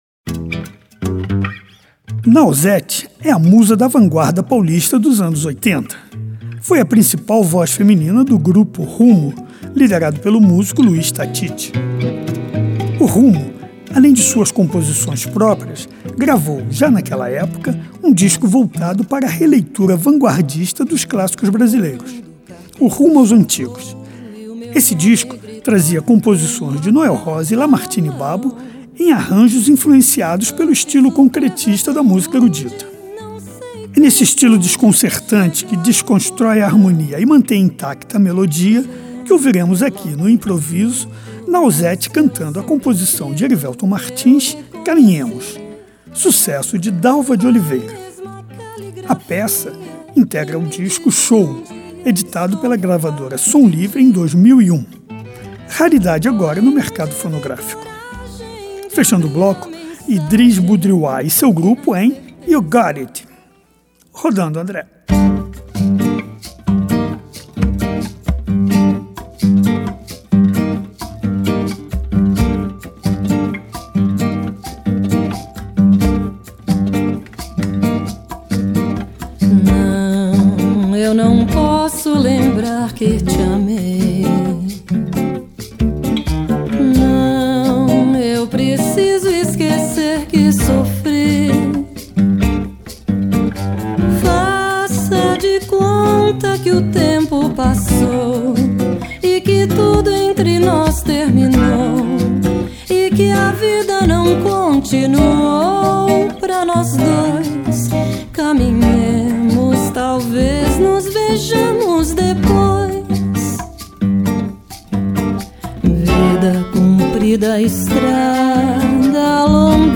traz o samba canção como referência
Música Jazz Samba